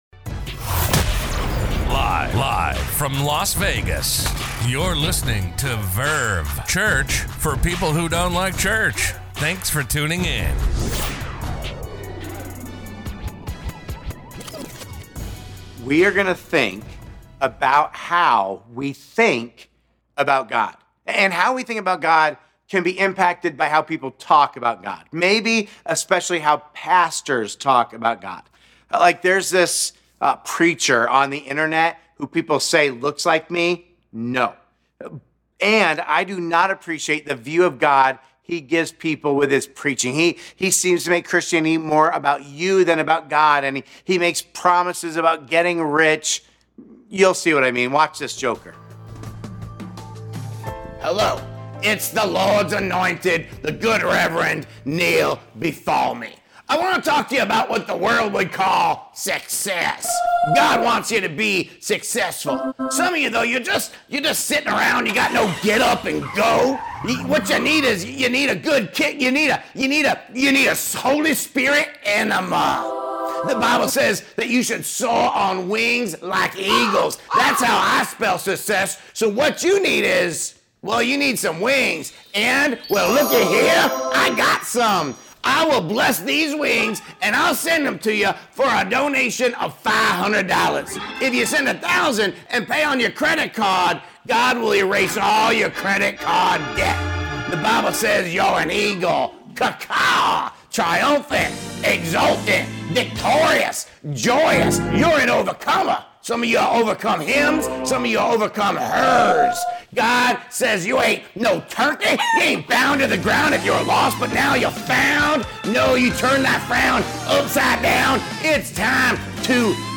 A message from the series "Not God Enough.."